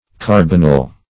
Carbonyl \Car"bon*yl\, n. [Carbon + -yl.] (Chem.)